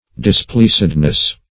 Displeasedness \Dis*pleas"ed*ness\, n.
displeasedness.mp3